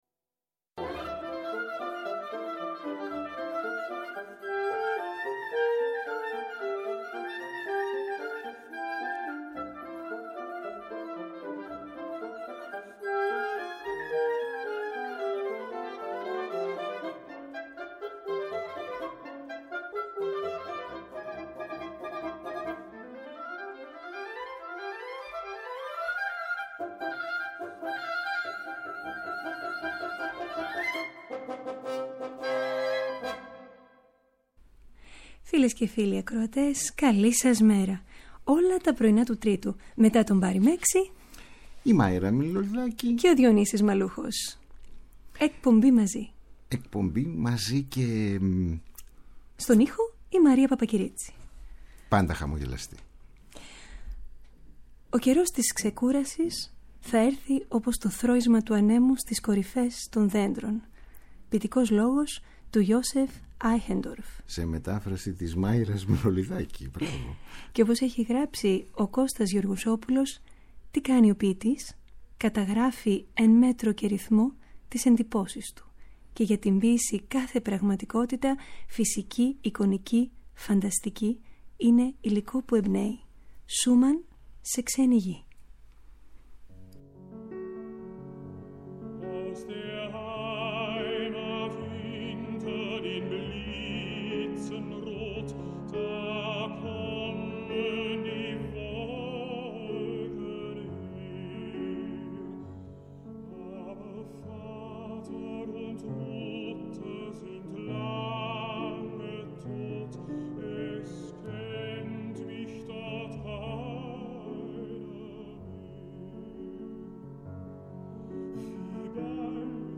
Η πολυχρωμία της μουσικής ακρόασης μέσα από ένα ευρύ ρεπερτόριο έργων, με αφορμή την καλλιτεχνική κίνηση της εβδομάδας (συναυλίες, εκθέσεις και παραστάσεις, φεστιβάλ και εκδηλώσεις, νέες κυκλοφορίες ηχογραφήσεων και εκδόσεις).